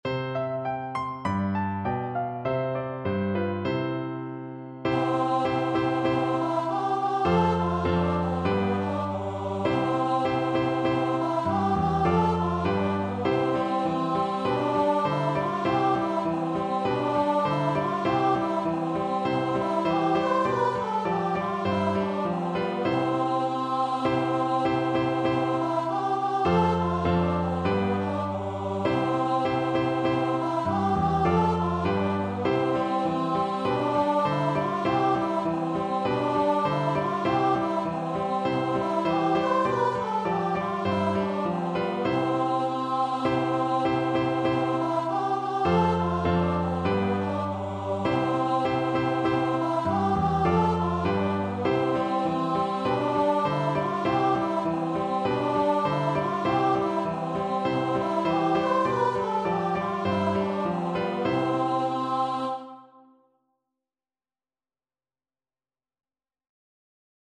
G4-C6
2/2 (View more 2/2 Music)
Two in a bar =c.100